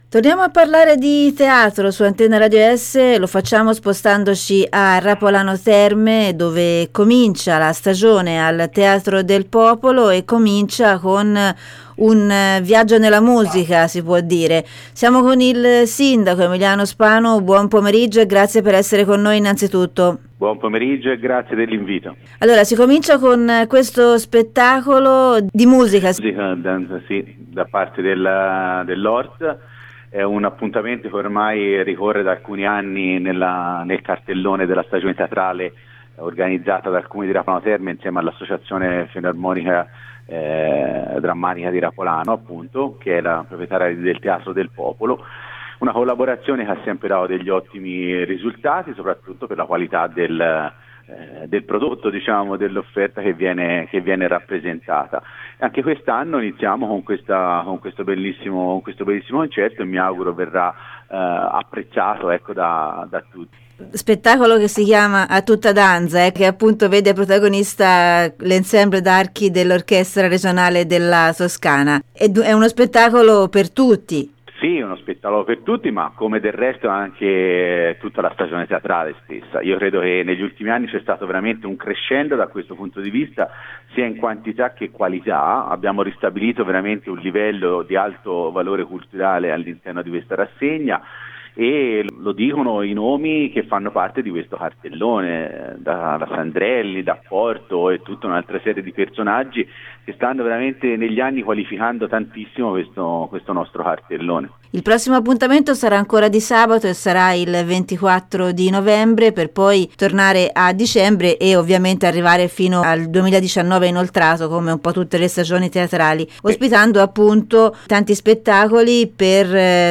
ASCOLTA L’INTERVISTA DEL SINDACO EMILIANO SPANU